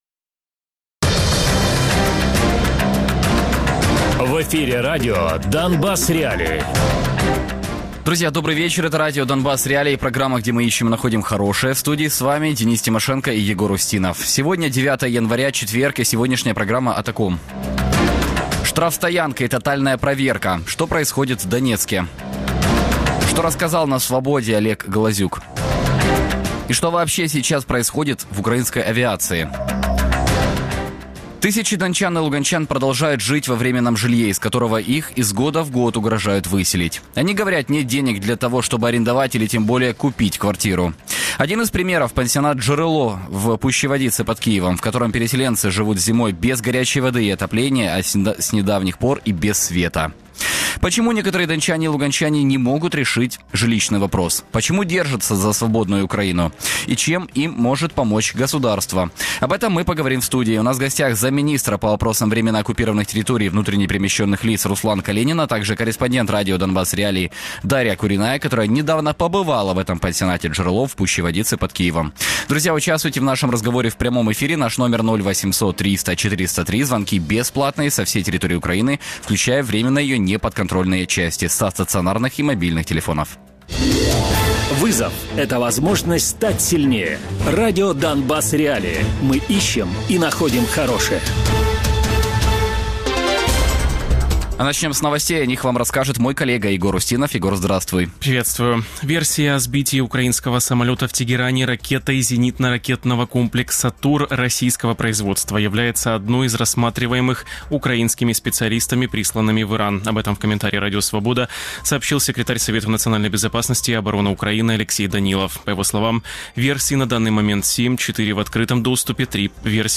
журналістка Радіо Донбасс.Реаліі. Радіопрограма «Донбас.Реалії» - у будні з 17:00 до 18:00.